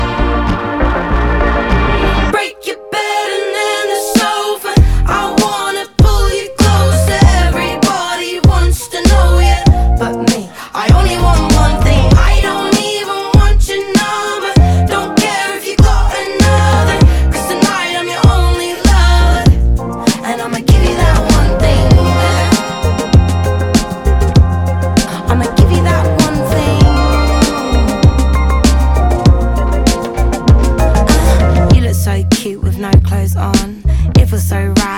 2025-05-16 Жанр: Альтернатива Длительность